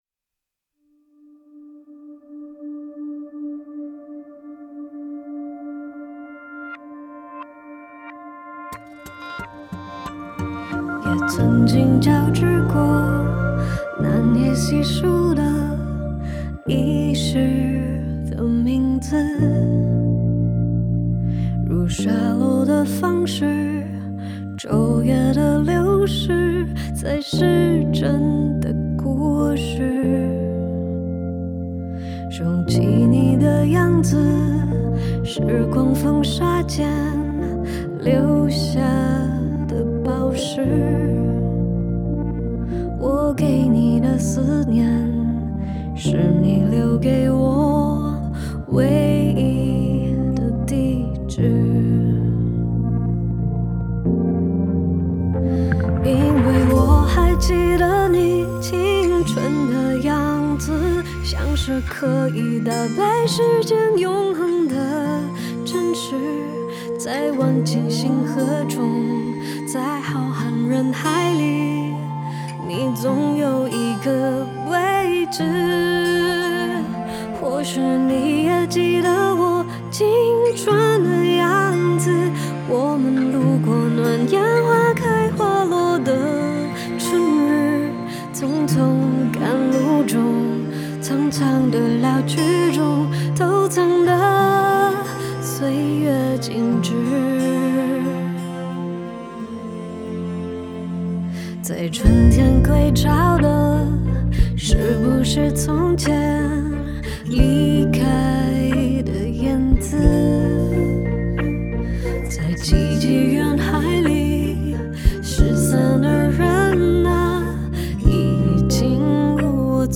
吉他
弦乐